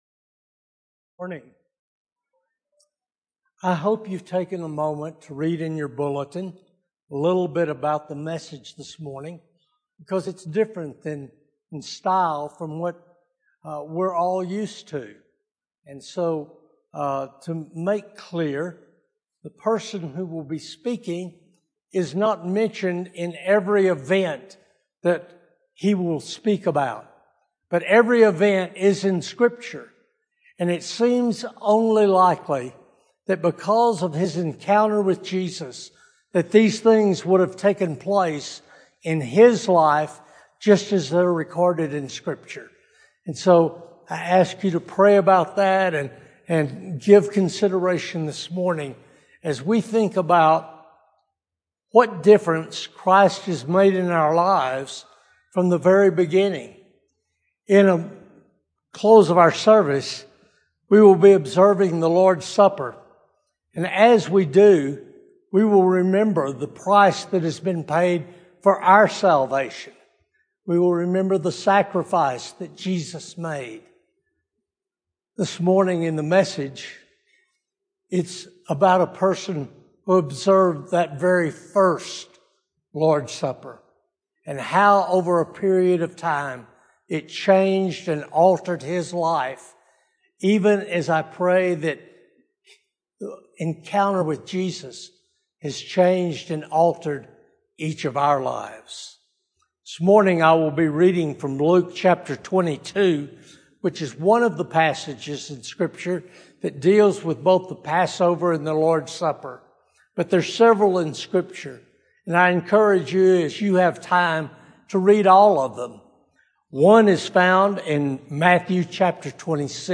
Sermons - Vista Grande Baptist Church